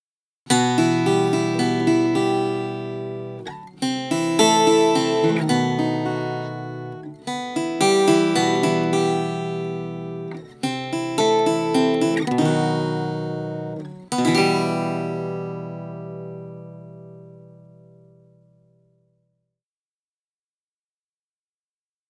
Viola Caipira Regis Bonilha
Une clarté et un sustain d’ enfer. Je l’ utilise souvent façon tres cubain……
viola.mp3